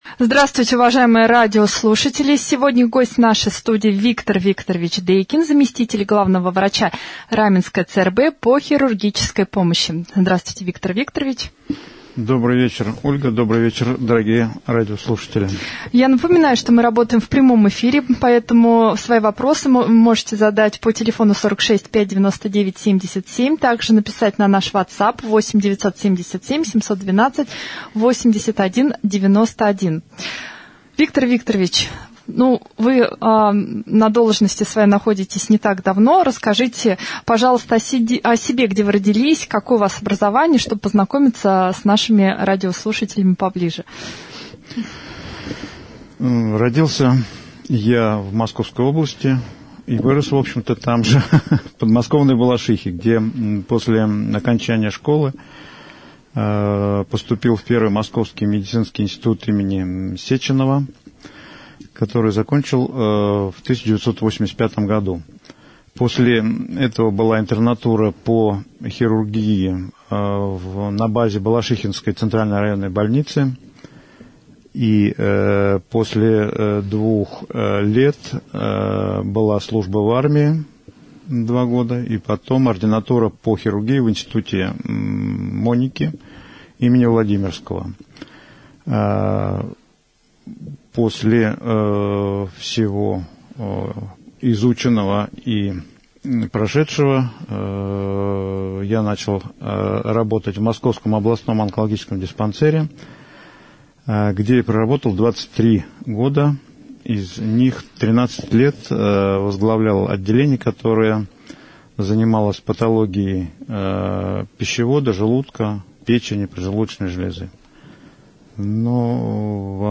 В прямом эфире мы обсудили порядок плановой госпитализации, новое оборудование, поступившее в этом году в распоряжение Раменской ЦРБ и специалистов, на нем работающих.